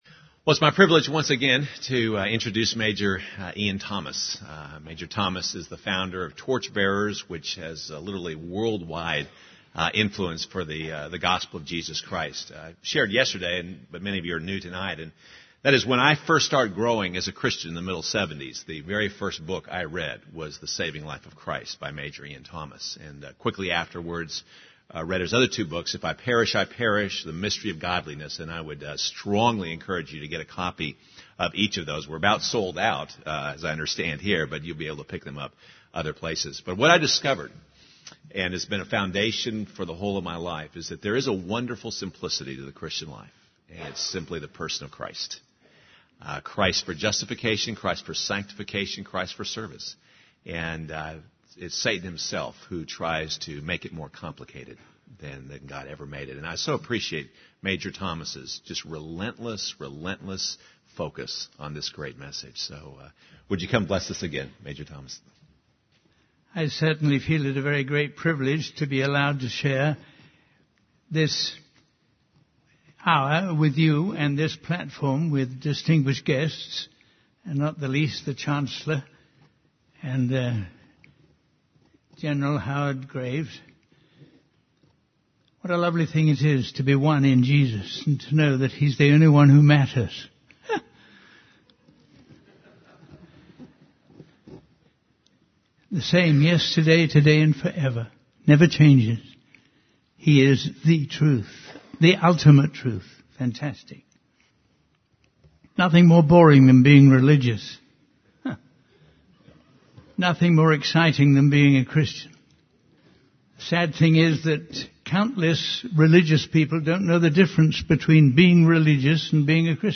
In this sermon, the speaker emphasizes the importance of following the instructions given by God.